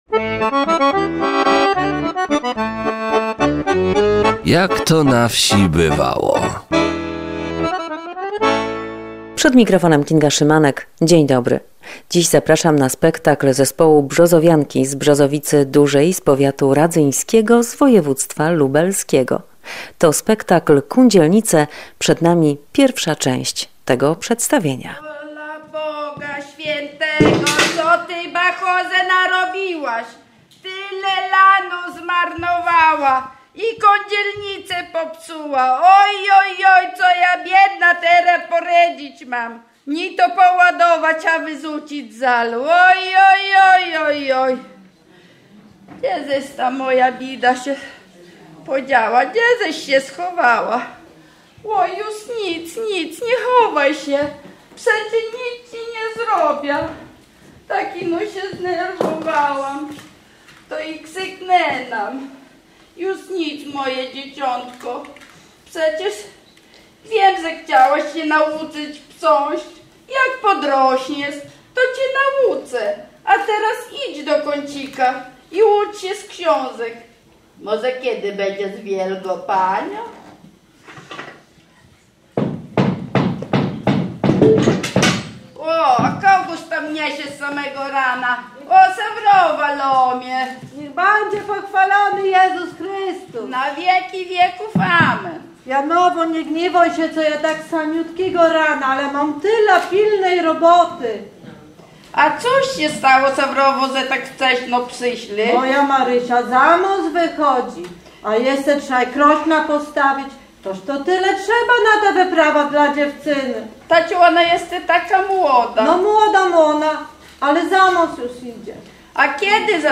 Widowisko obrzędowe "Kundzielnice" w wykonaniu zespołu Brzozowianki z Brzozowicy Dużej.